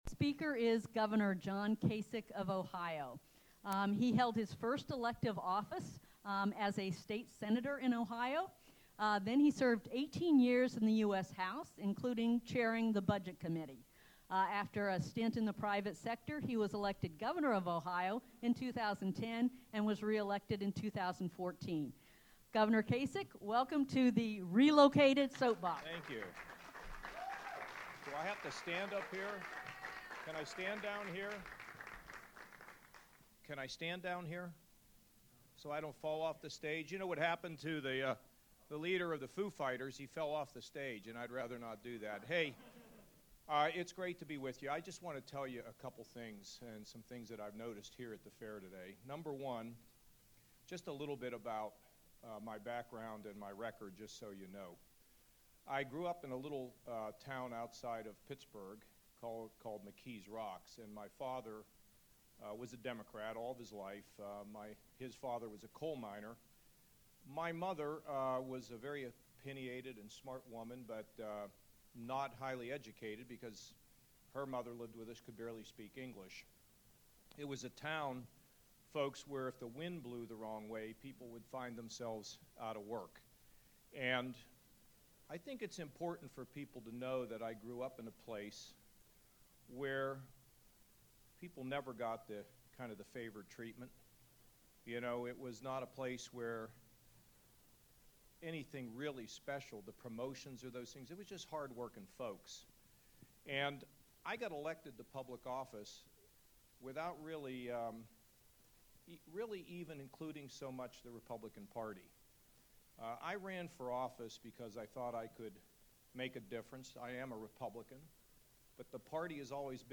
John Kasich at the Des Moines Register’s Soap Box, which was moved inside because of the rain.
Republican presidential candidate John Kasich stressed “the value of teamwork” during an appearance at the Iowa State Fair today.